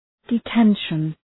Shkrimi fonetik {dı’tenʃən}